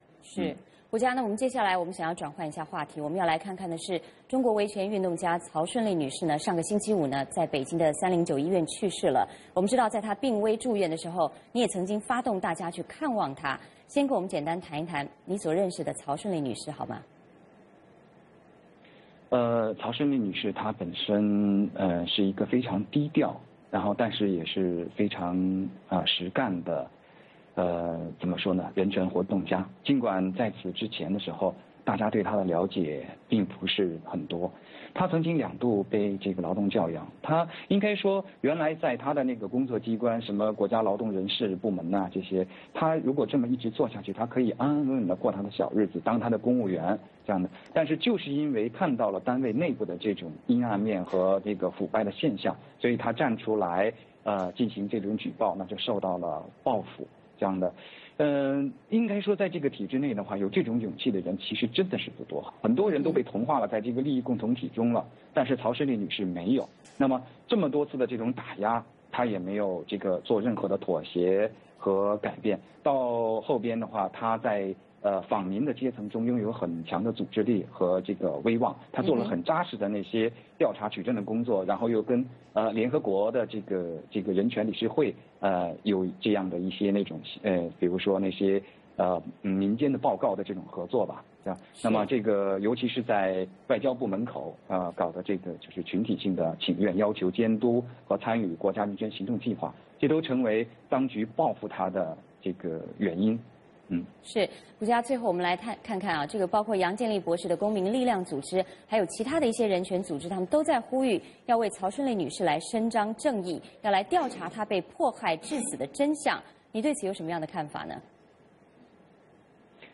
包括杨建利博士的公民力量和其他人权组织呼吁各界为曹顺利伸张正义，调查她被迫害致死的真相。我们通过连线，请胡佳先生来谈谈他所认识的曹顺利。